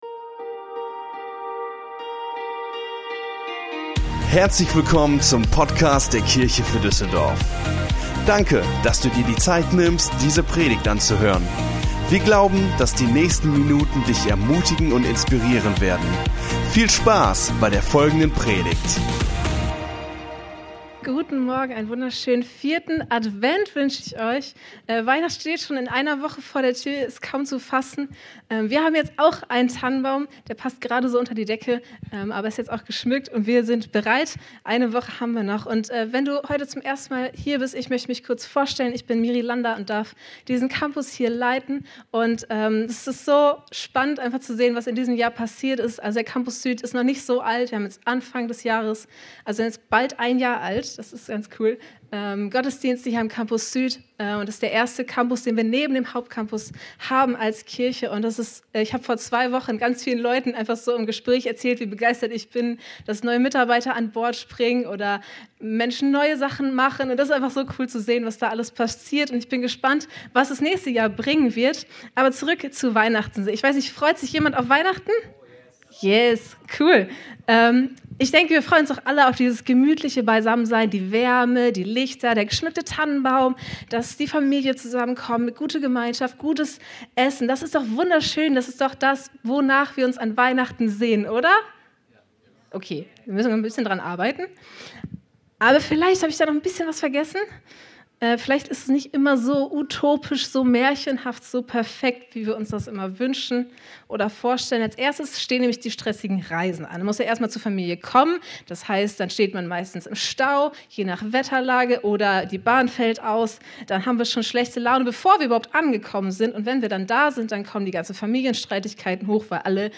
Unsere Predigt vom 18.12.22 Predigtserie: Gute Nachrichten Teil 3 Folge direkt herunterladen